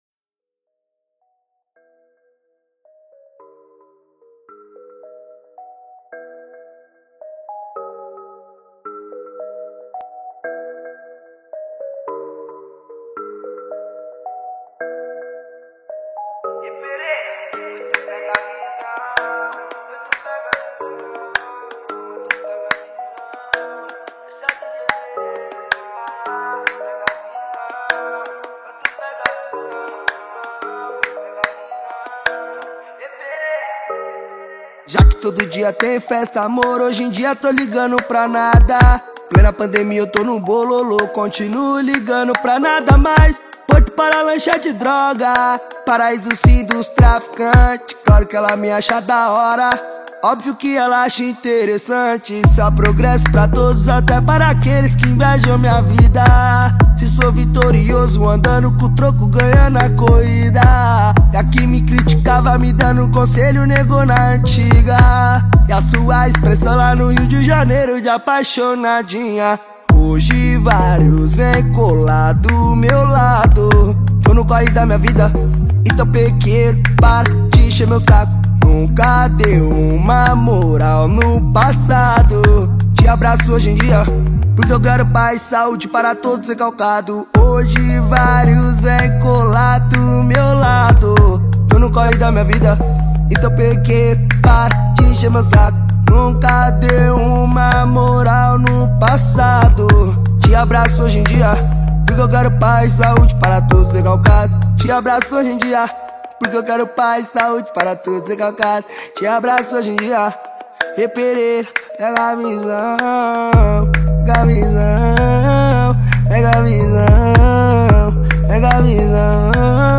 2024-07-25 14:36:30 Gênero: Funk Views